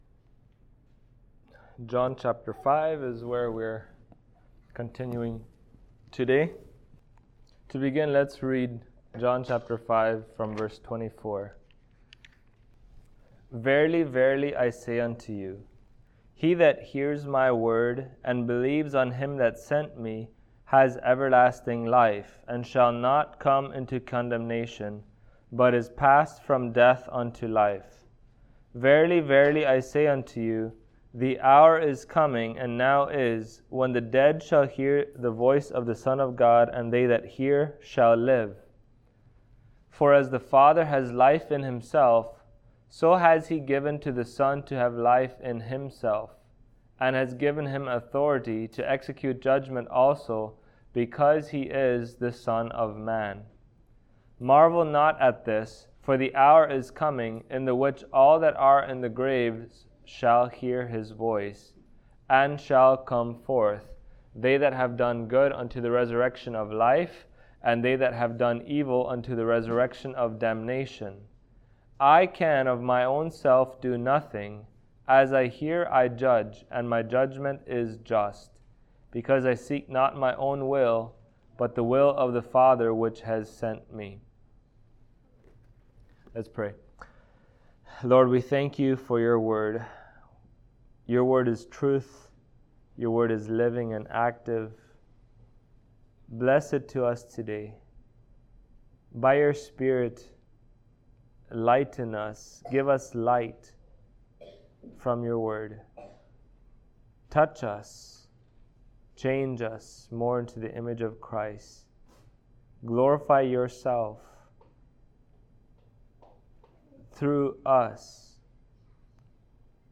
Passage: John 5:24-30 Service Type: Sunday Morning Topics